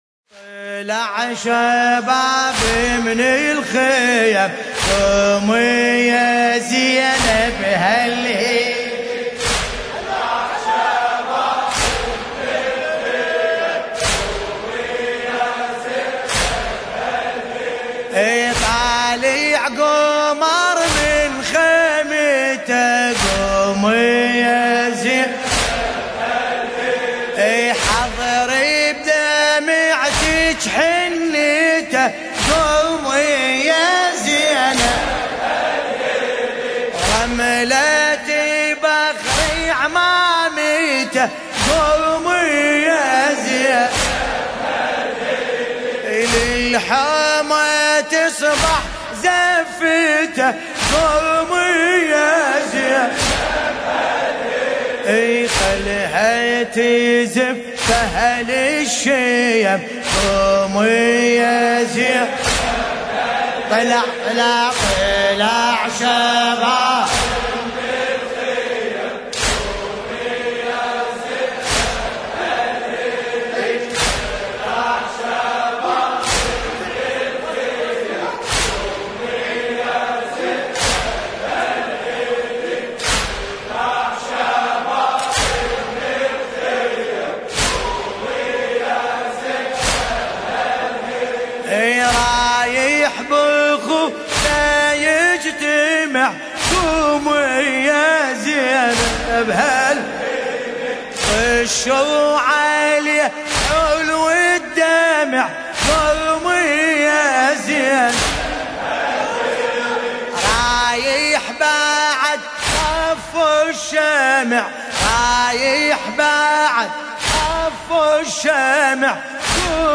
قصيدة : طلع شباب من الخيم
المناسبة : اسشتهاد القاسم ابن الامام الحسن (ع)
الزمن : ليلة 8 محرم 1440 هـ
هيئة شباب الأكبر - لندن